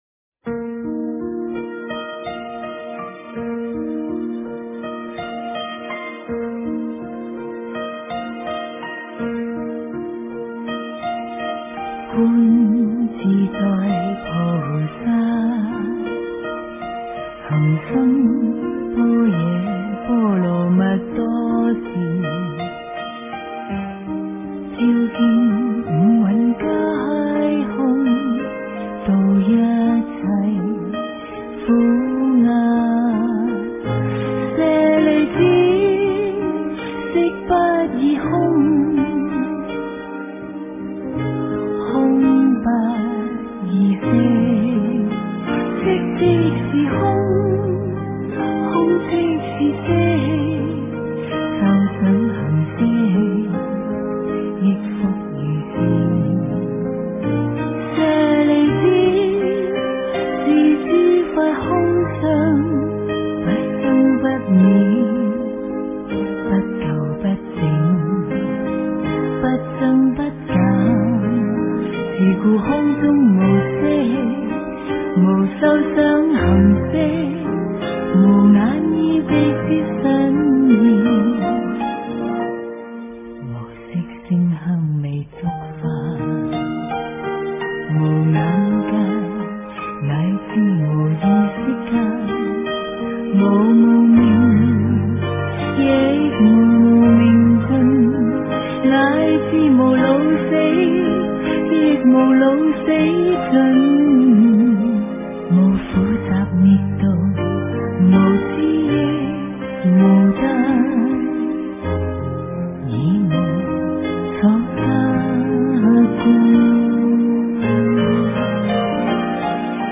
心经 - 诵经 - 云佛论坛
心经 诵经 心经--佚名 点我： 标签: 佛音 诵经 佛教音乐 返回列表 上一篇： 大悲咒 下一篇： 般若波罗蜜多心经 相关文章 唵嘛呢叭咪吽-纯音乐--水晶佛乐 唵嘛呢叭咪吽-纯音乐--水晶佛乐...